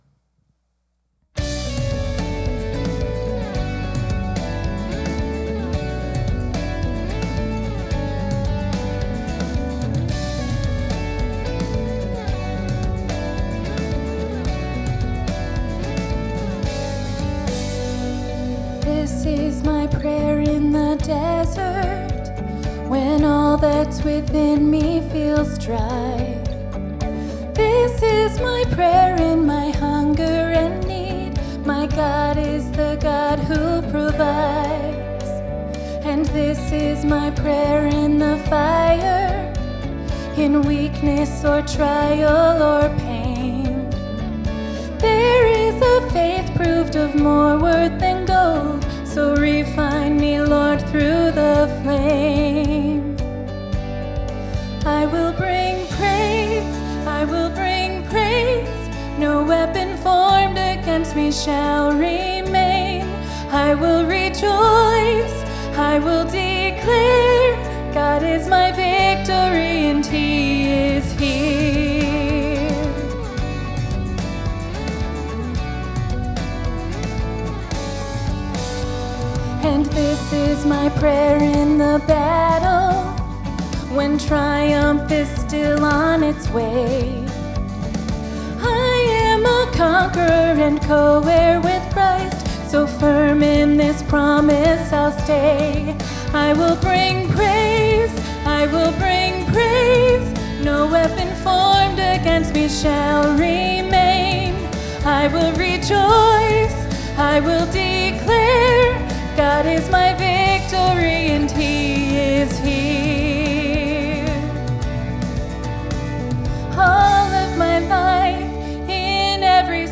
Sunday Morning Music
Solo